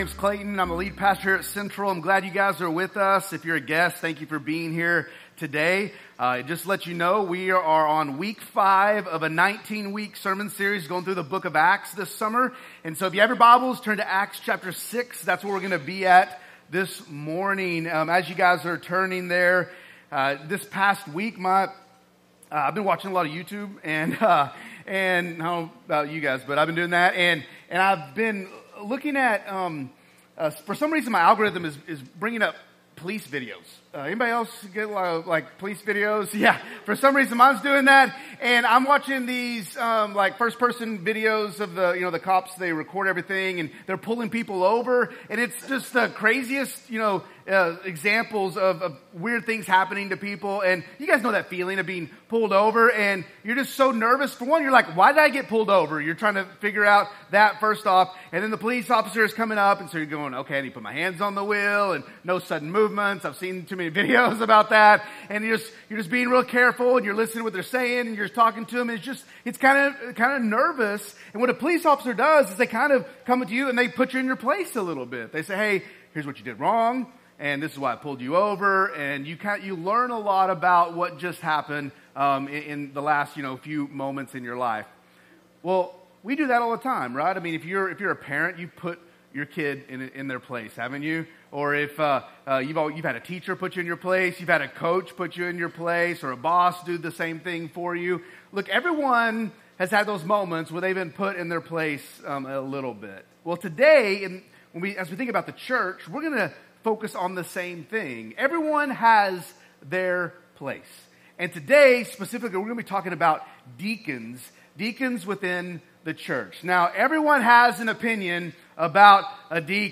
"So They May Hear," our summer sermon series deep diving into the book of Acts